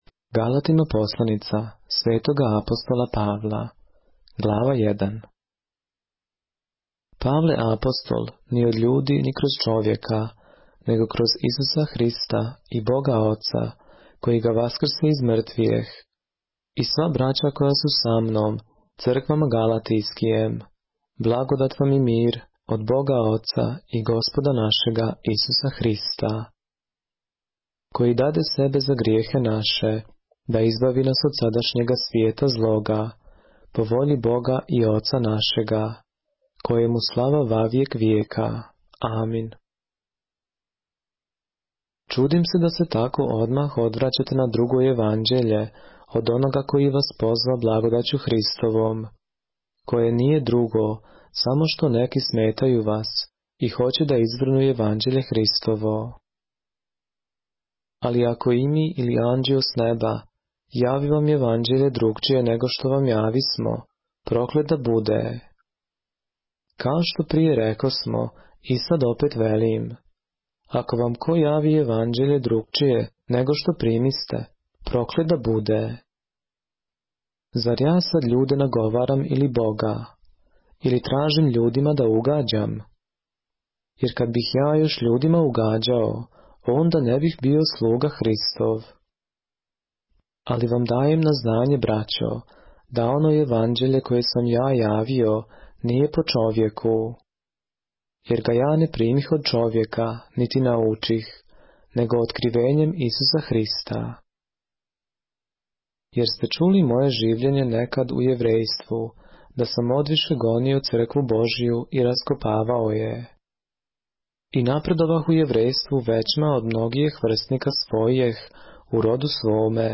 поглавље српске Библије - са аудио нарације - Galatians, chapter 1 of the Holy Bible in the Serbian language